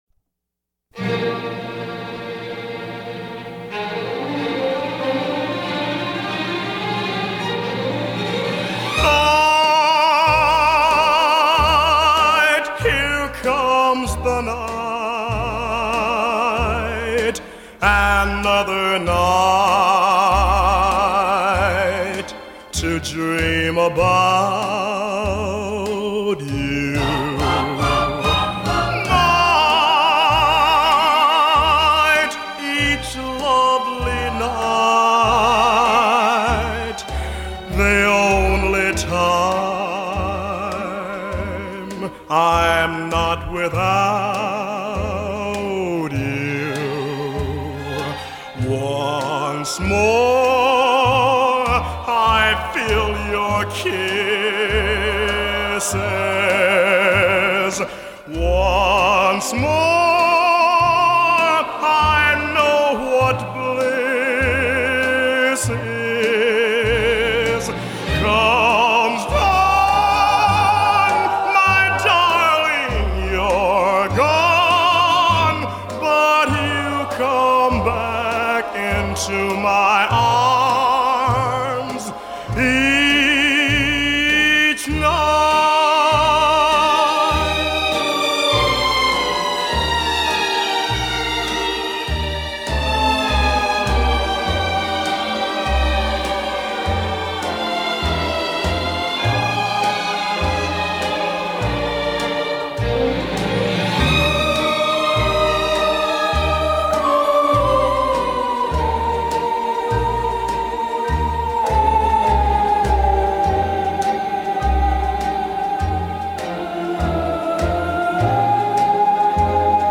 13 Over-the-Top Vocal Performances of The 1960s